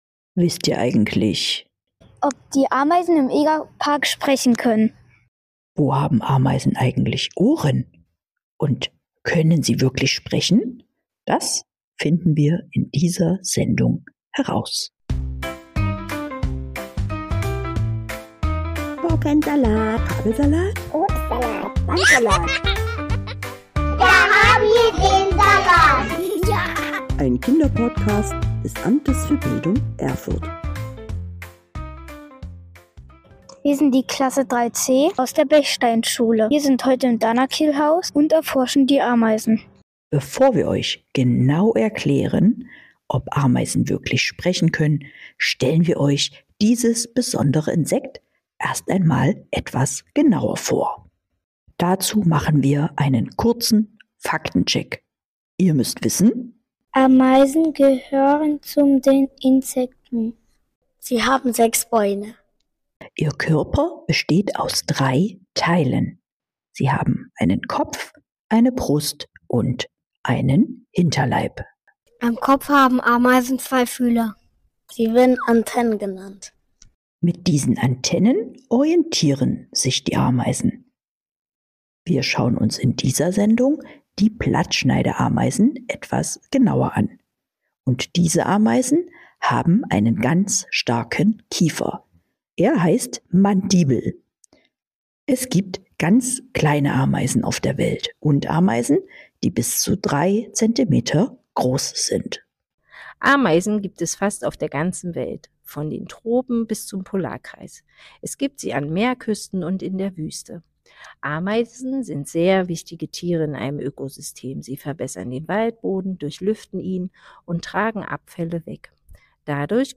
Mit einem speziellen Mikrofon versuchen wir die Geräusche im egapark aufzunehmen.